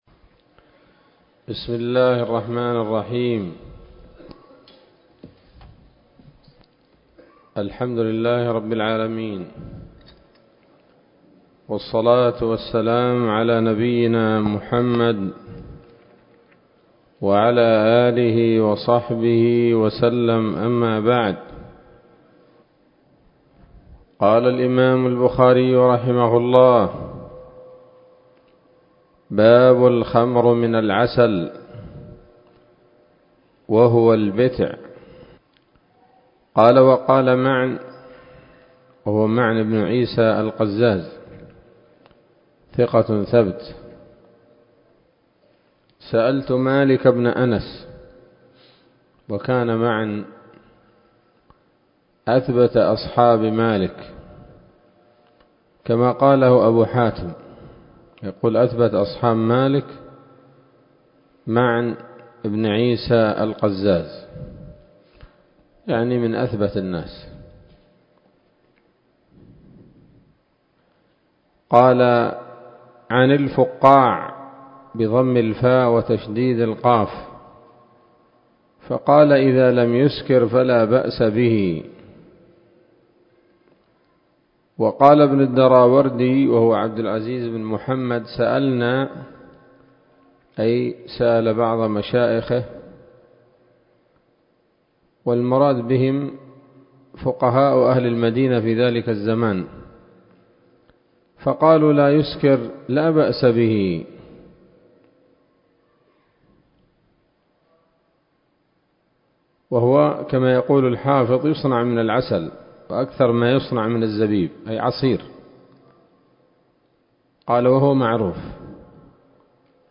الدرس الرابع من كتاب الأشربة من صحيح الإمام البخاري